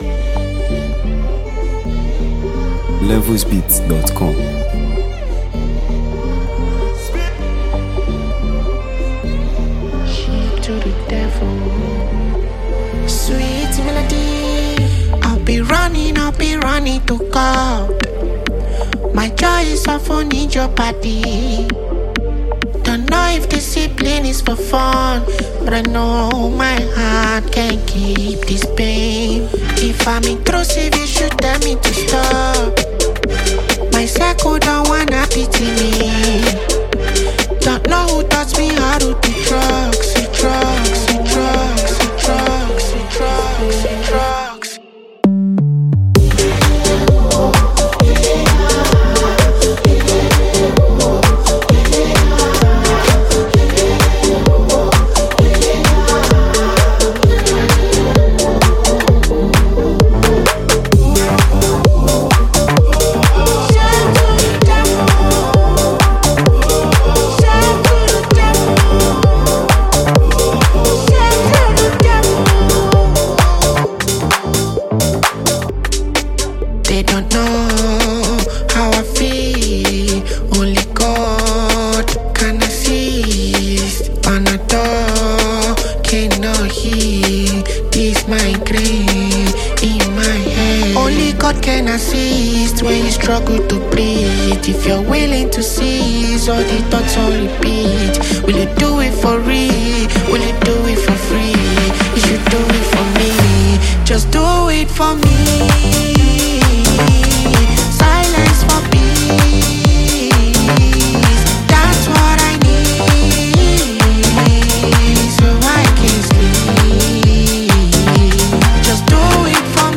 Nigeria Music 2025 3:00
emotionally charged track
smooth rhythm